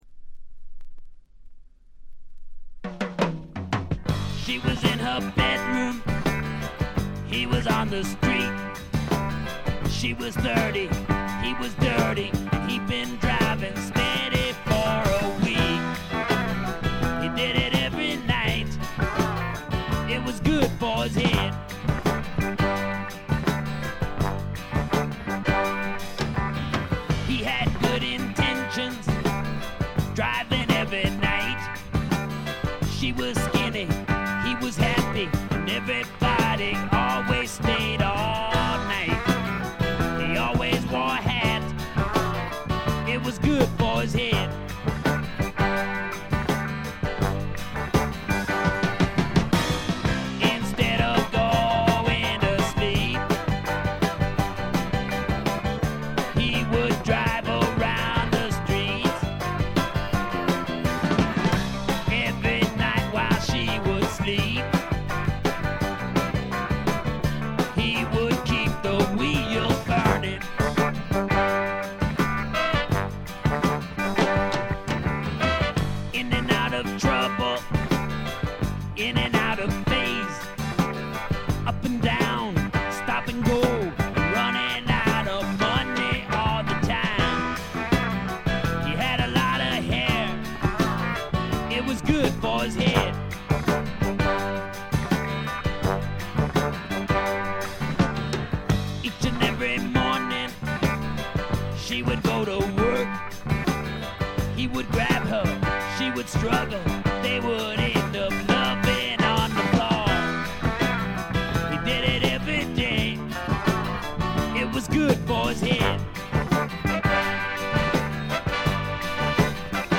部分試聴ですが、ところどころで軽微なチリプチ、散発的なプツ音少し。
試聴曲は現品からの取り込み音源です。